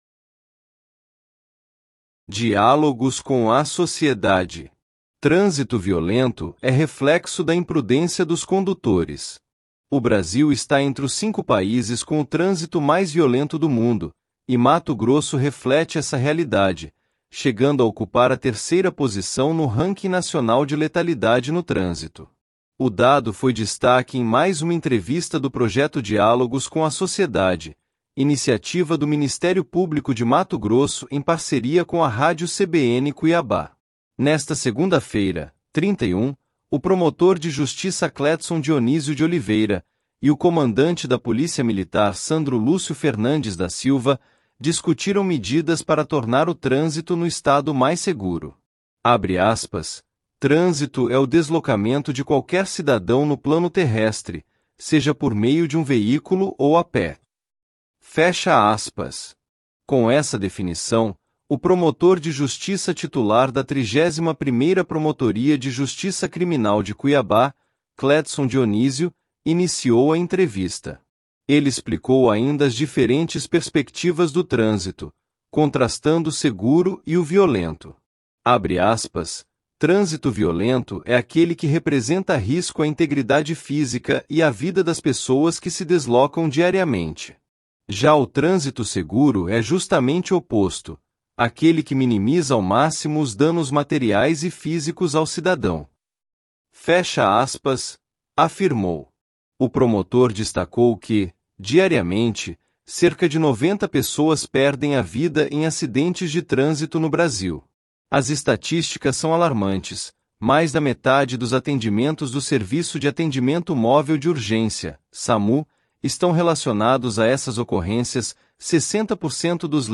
As entrevistas do projeto Diálogos com a Sociedade seguem até o dia 11 de abril, das 14h às 15h, no estúdio de vidro localizado na entrada principal do Pantanal Shopping, com transmissão ao vivo pelo canal do MPMT no YouTube.